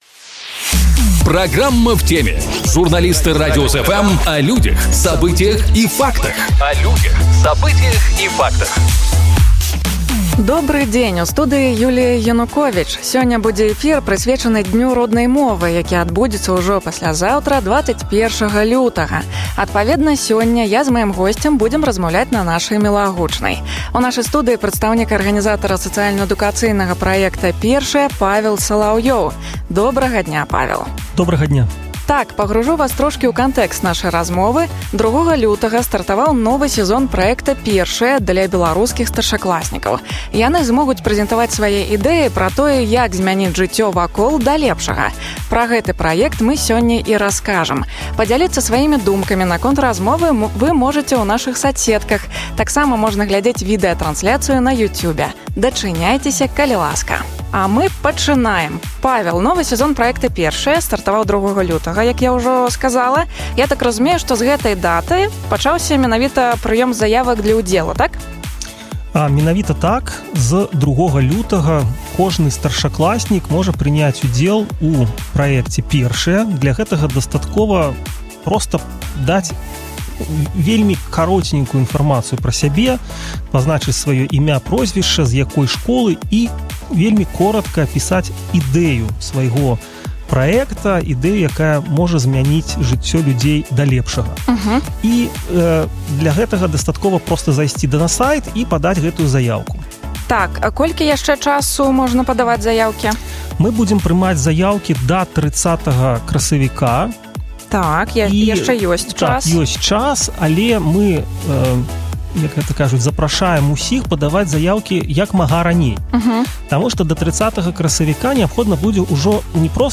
Сёння будзе эфір, прысвечаны дню Роднай мовы, які адбудзецца ўжо паслязаўтра, 21 лютага.